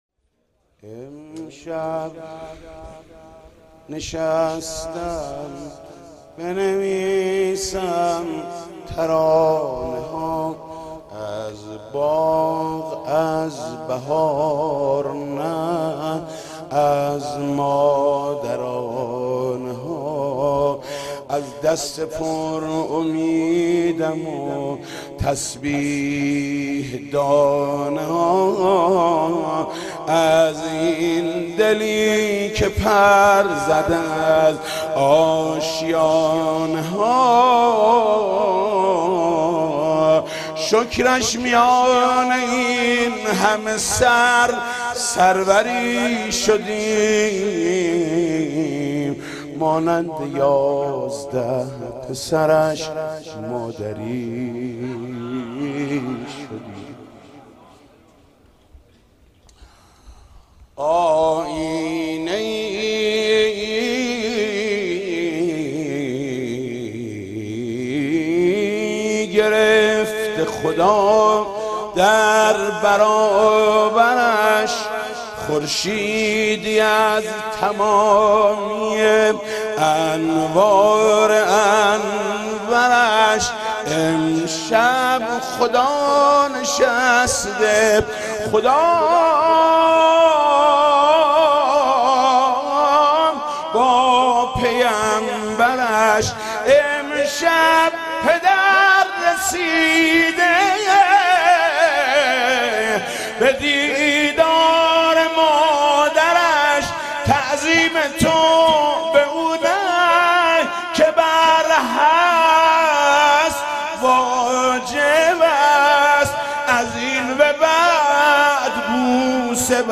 «میلاد امام رضا 1392» مدح: امشب نشسته ام بنویسم ترانه ها